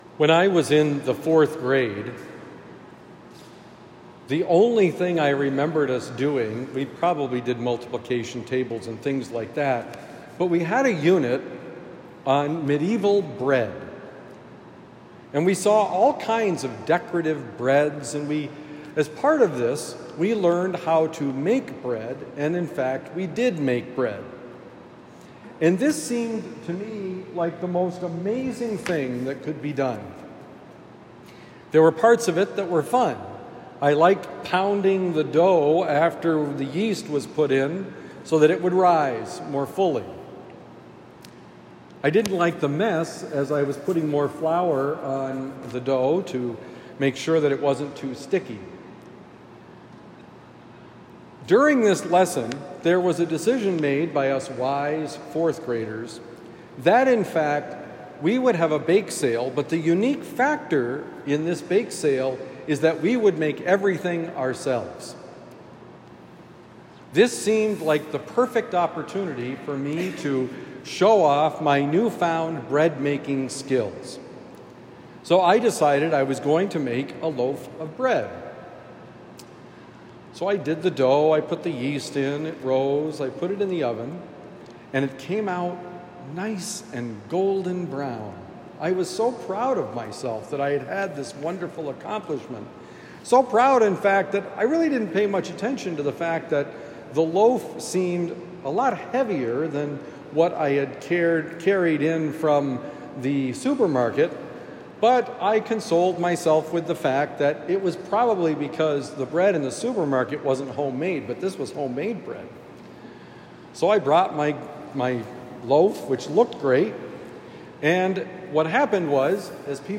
My bread is not the bread of life: Homily for Sunday, August 4, 2024